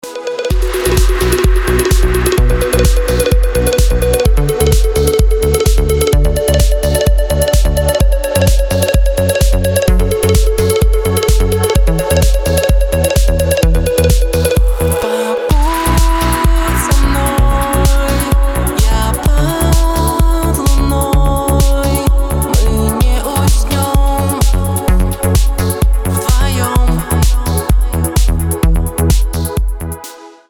Kategória: Külföldi
Minőség: 320 kbps 44.1 kHz Stereo